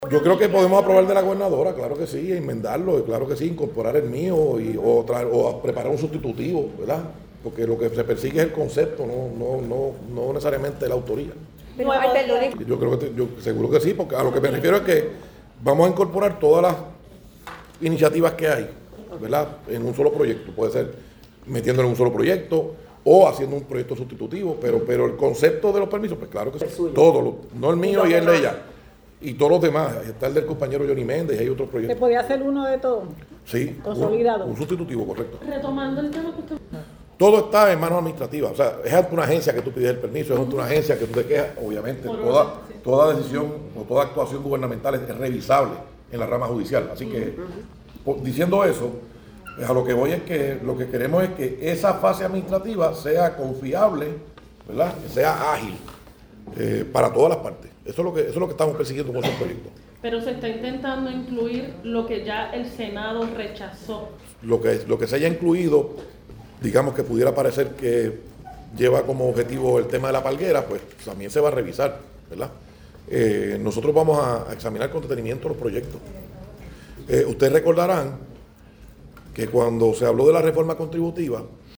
(13 de abril de 2026)-Hoy se llevó a cabo Vista pública conjunta de la Comisión de Innovación, Reforma y Nombramientos que dirige el presidente del Senado, Thomas Rivera Schatz, y la Comisión de Planificación, Permisos, Infraestructura y Urbanismo que preside el senador Héctor “Gaby” González López.